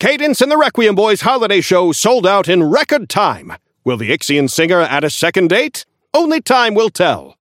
Newscaster_seasonal_headline_23.mp3